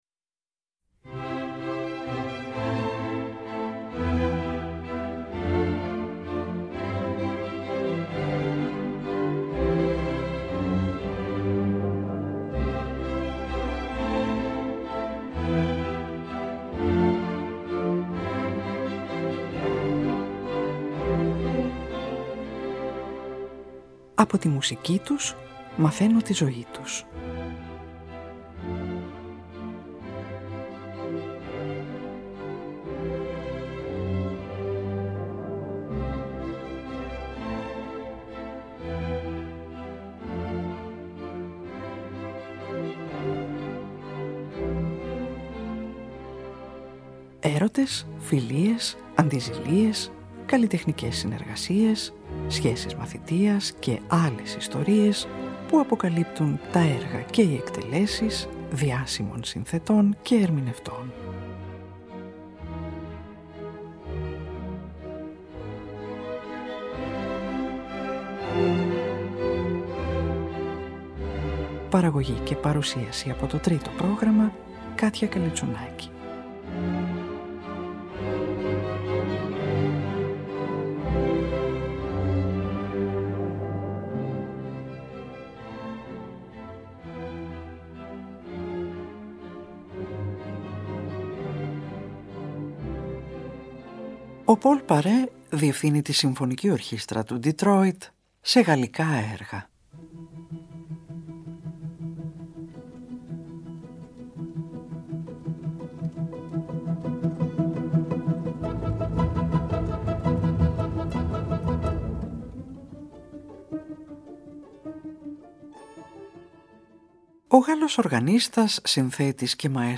O Paul Paray Διευθύνει τη Συμφωνική Ορχήστρα του Detroit σε Γαλλικά Έργα